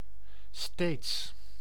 Ääntäminen
IPA: [steːds]